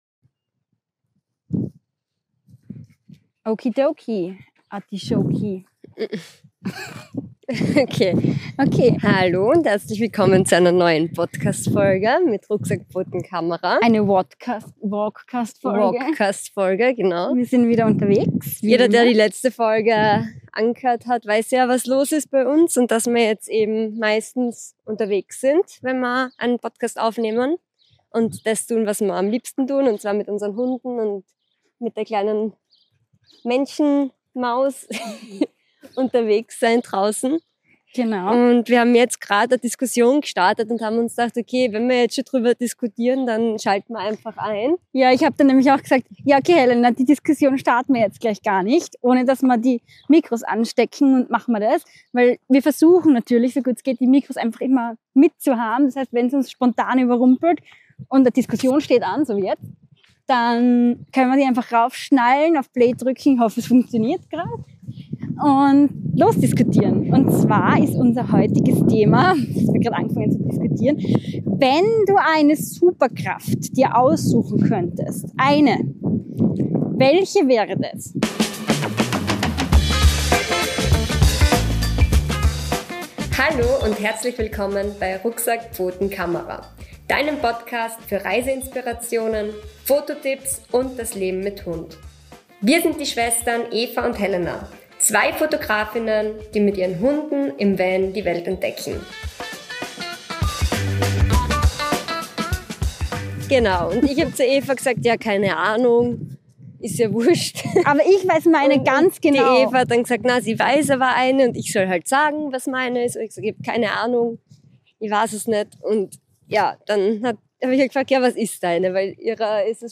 In dieser völlig unnötigen Podcastfolge nehmen wir euch mit auf einen Spaziergang, auf dem eine absurde Diskussion über Superkräfte beginnt....
Ungefiltert, spontan und total sinnlos – aber hey, genau dafür lieben wir diese Walkcasts.